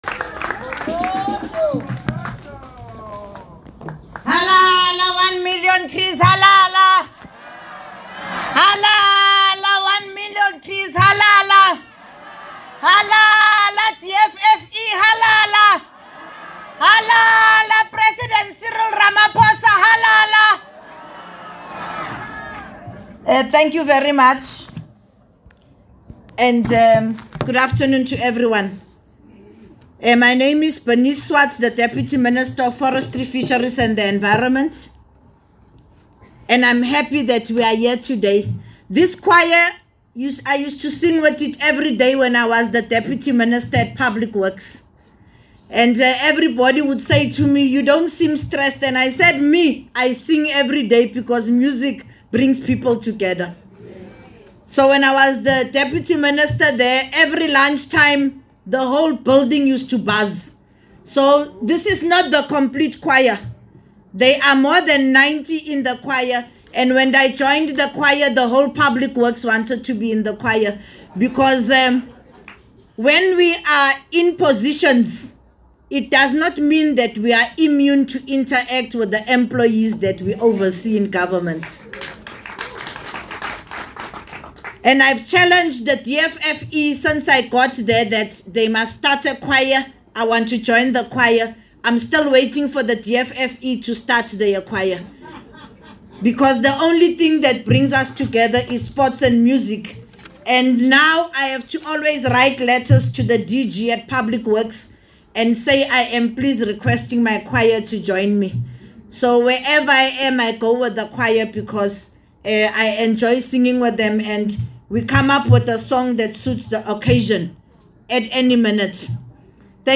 Deputy Minister B Swarts: launch of the One Million Trees Campaign
07 July 2025 | Pretoria National Botanical Garden, South African National Biodiversity Institute (SANBI)